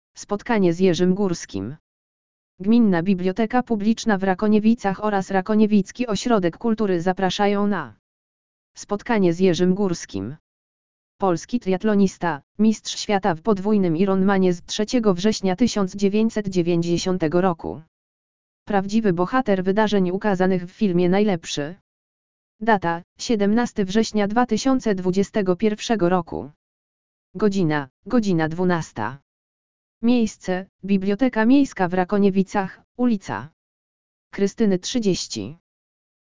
audio_lektor_wydarzenie_spotkanie_z_jerzym_gorskim.mp3